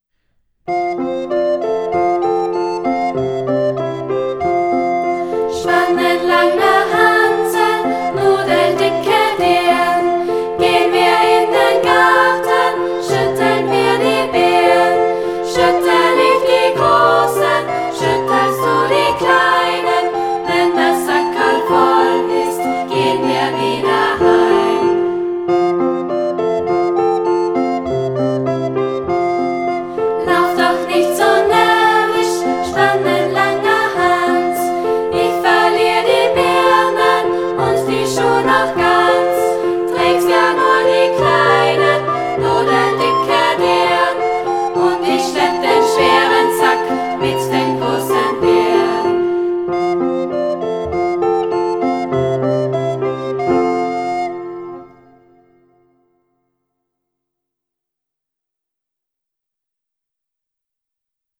Diese Sammlung von klassischen Kinderliedern haben unsere Schülerinnen und Schüler aus dem Musikzweig aufgenommen.